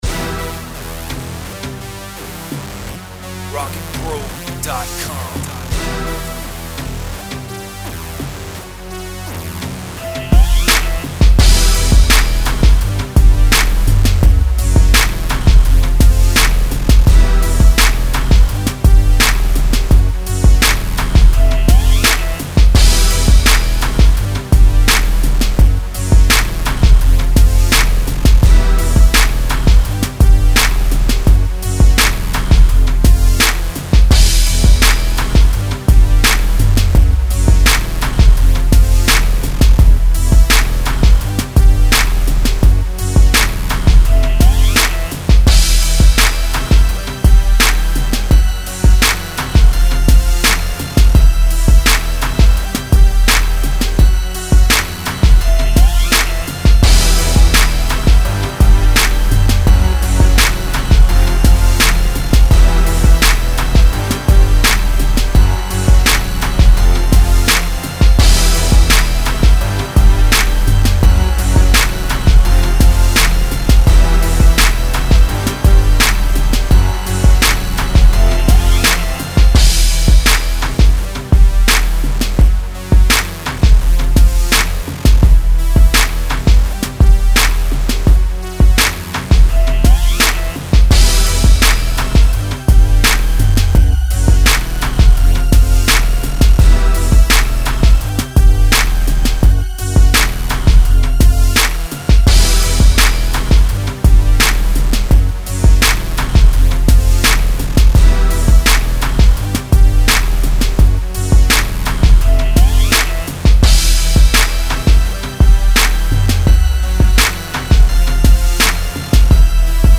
Gangsta/Street
Trap